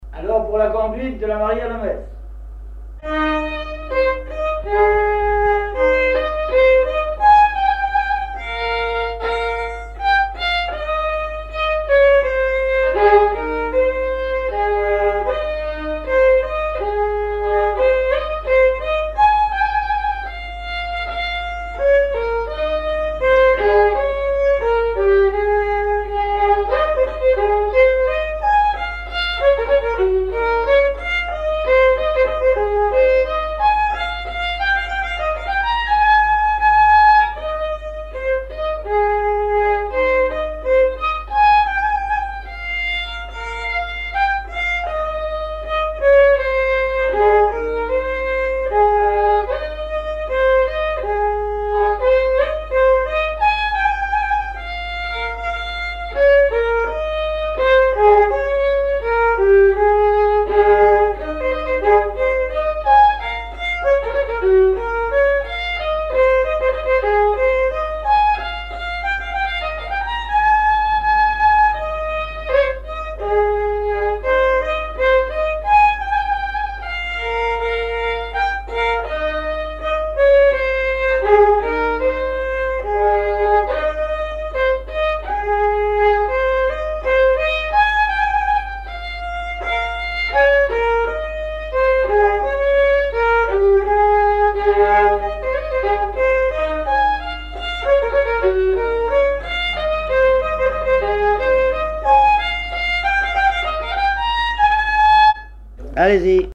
Saint-Vincent-Sterlange
recherche de répertoire de violon
Pièce musicale inédite